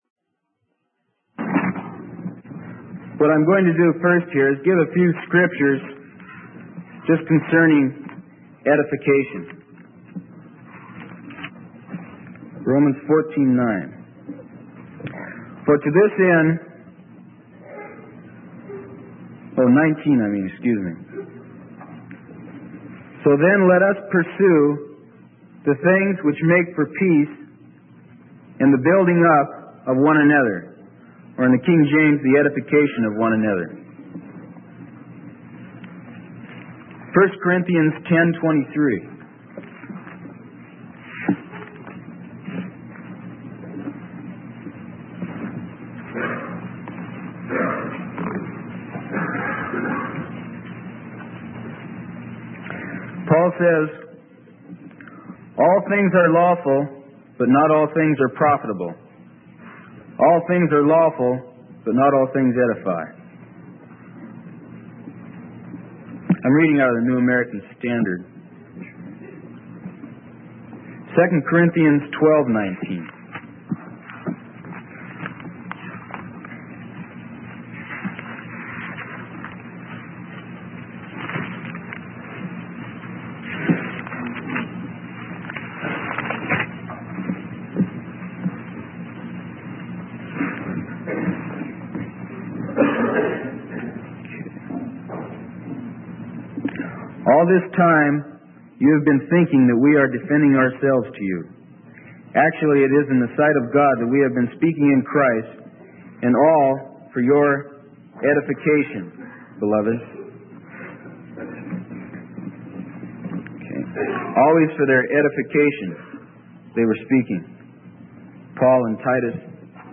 Sermon: Edification - Freely Given Online Library